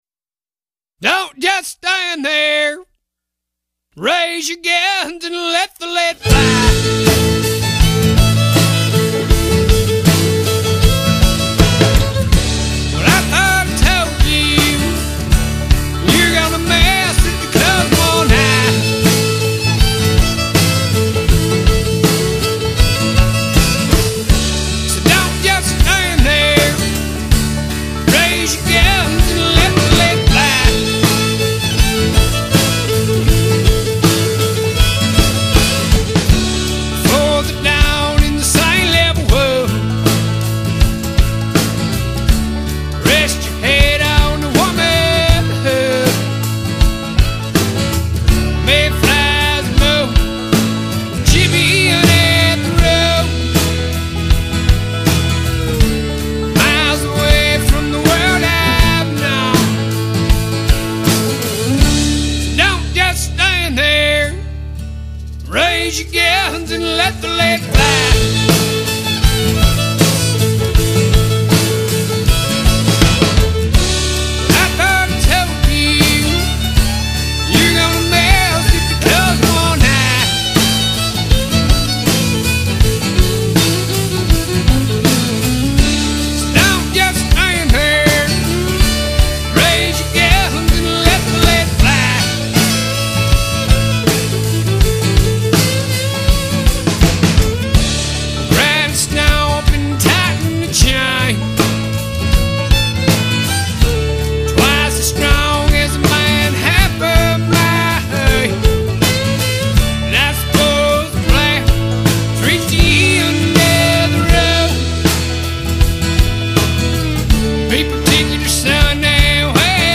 graveled Fogerty-like hollerin’